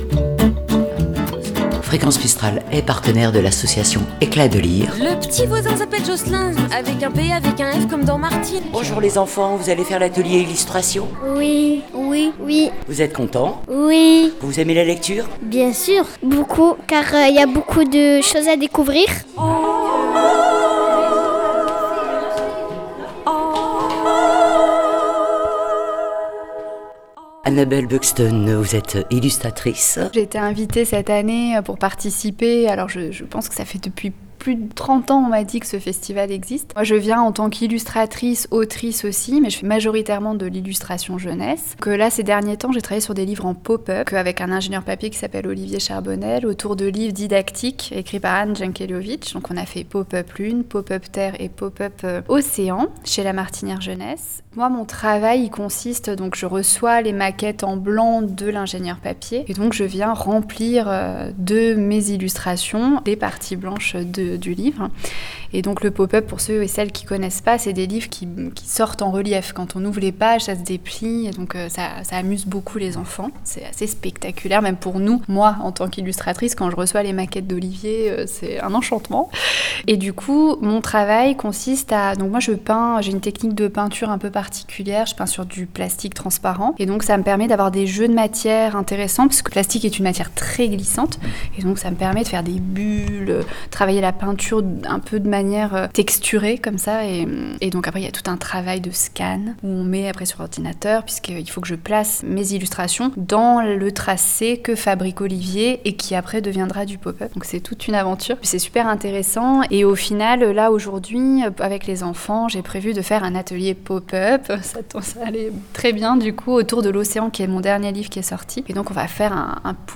Fréquence Mistral déambule dans les rues Manosquines et lieux, des ateliers, interviews, micro-trottoir... Des enfants, des adultes, des livres , de la curiosité ... et de la joie !